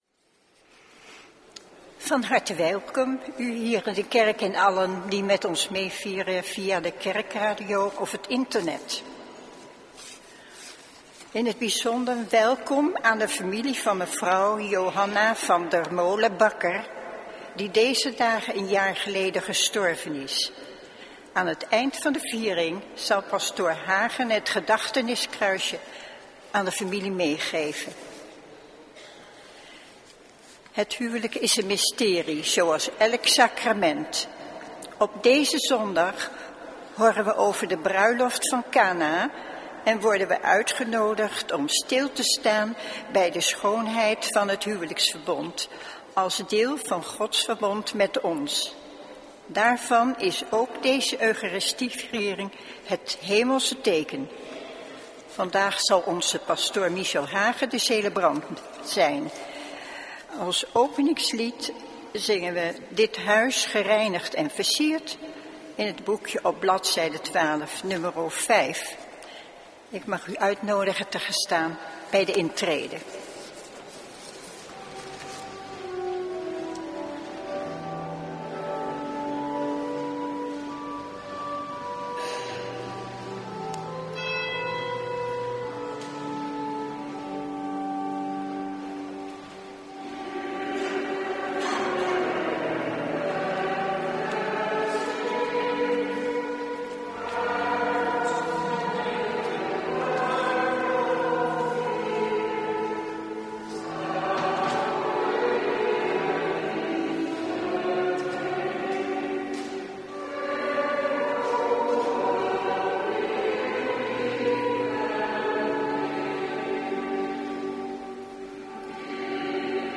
Eucharistieviering beluisteren vanuit de H. Willibrordus te Wassenaar (MP3)